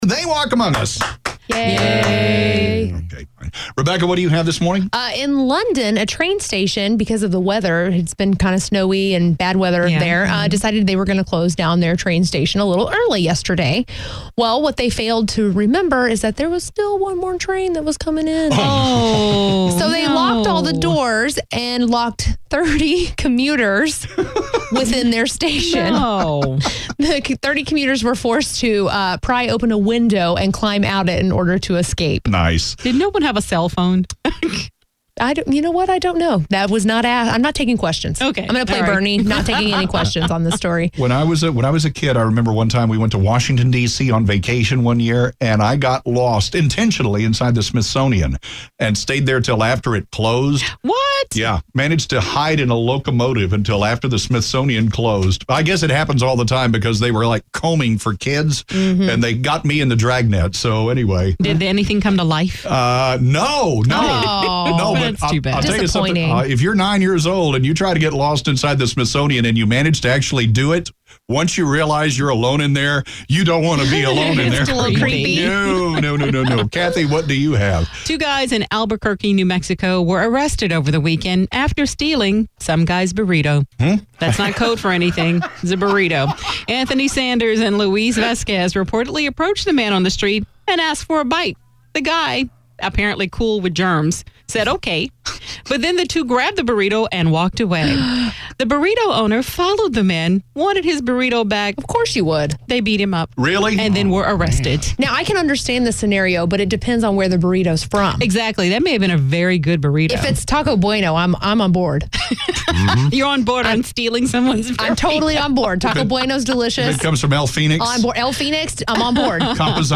UPDATE: February 8, 2012:  KLUV radio station featured this story on their “They Walk Among Us” program. Here is the audio of this broadcast, which follows a story about train commuters locked in the train station.